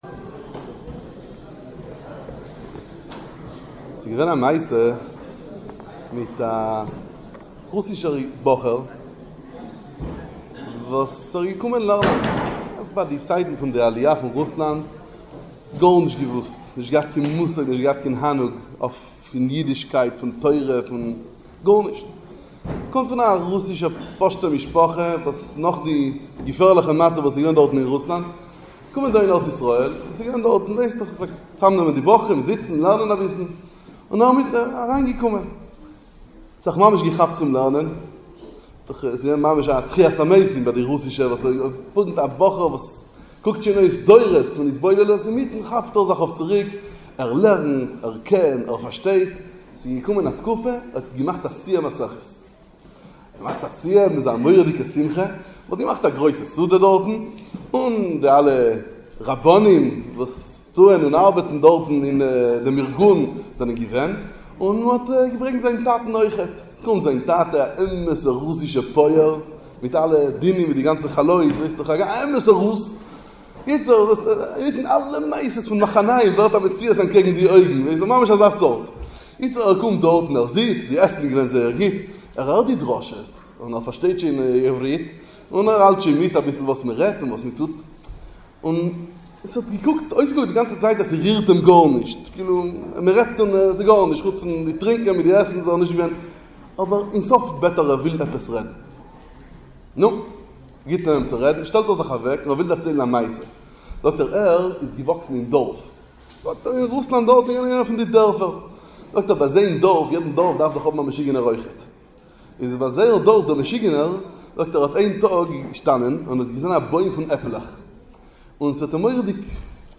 דרשה בחול המועד פסח, חיזוק ואמונה